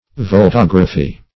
voltagraphy - definition of voltagraphy - synonyms, pronunciation, spelling from Free Dictionary
Search Result for " voltagraphy" : The Collaborative International Dictionary of English v.0.48: Voltagraphy \Vol*tag"ra*phy\, n. [Voltaic + -graphy.]